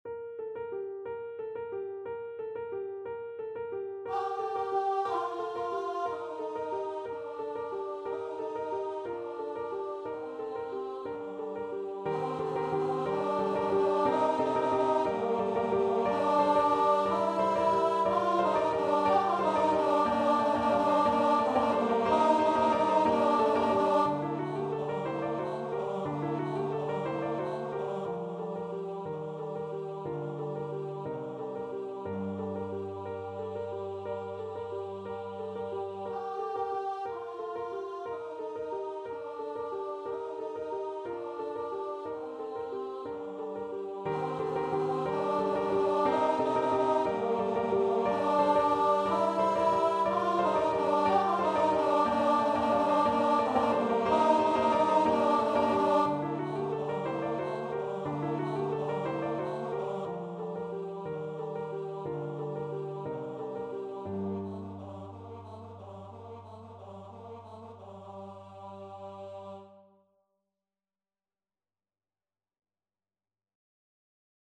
Free Sheet music for Choir (SATB)
3/4 (View more 3/4 Music)